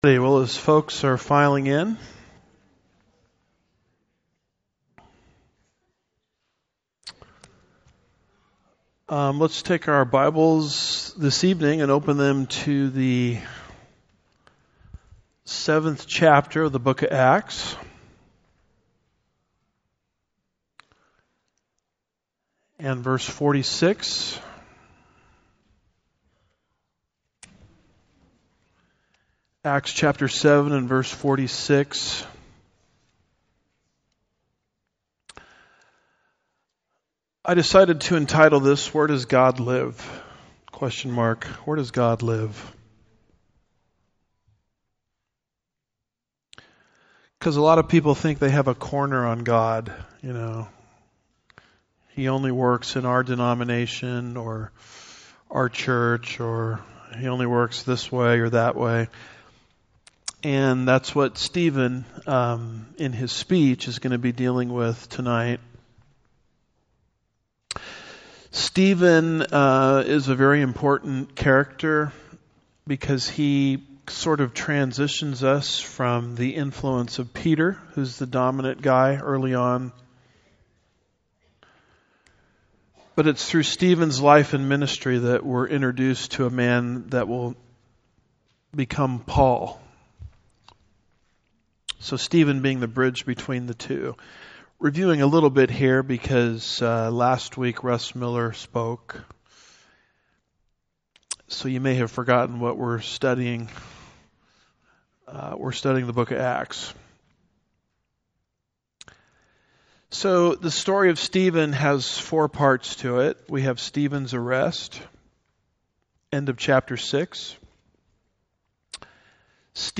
Home / Sermons / Acts 040 - Where Does God Live?